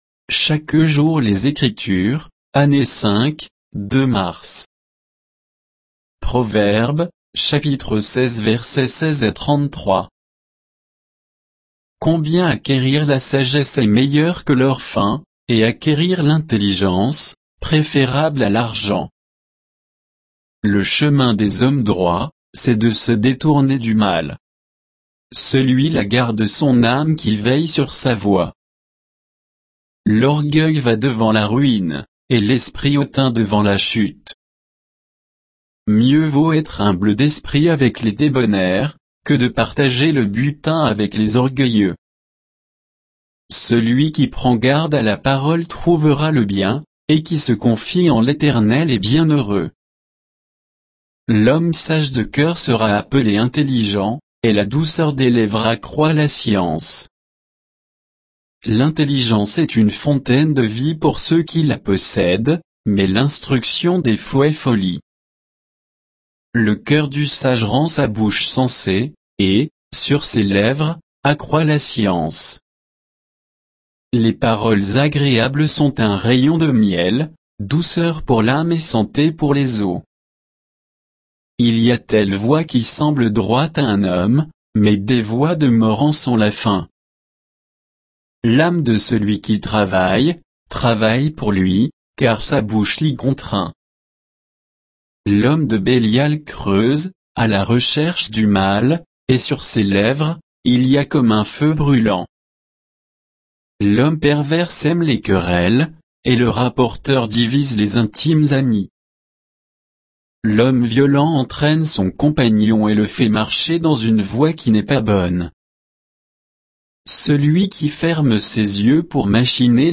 Méditation quoditienne de Chaque jour les Écritures sur Proverbes 16